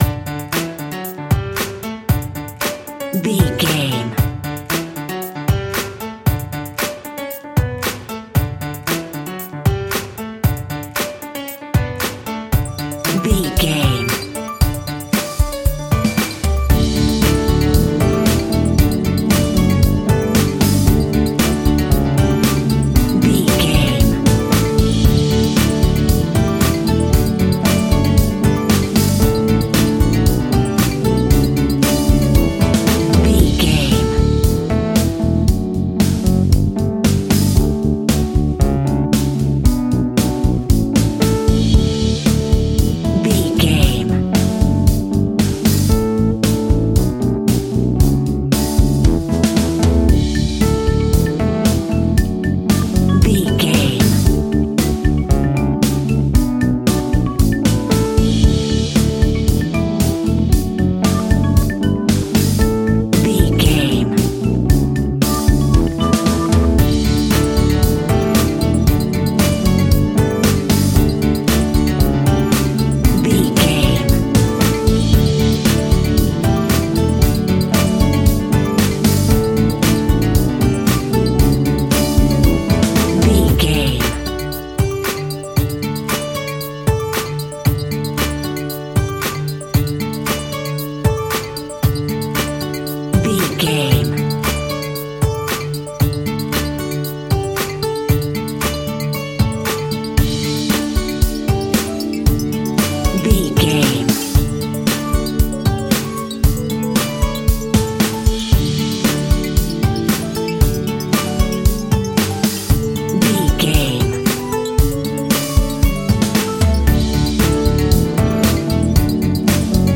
Ionian/Major
indie pop
indie rock
pop rock
sunshine pop music
drums
bass guitar
electric guitar
piano
hammond organ